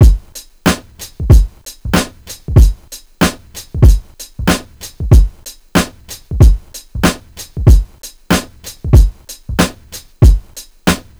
94 BPM_BABY BUBBA.wav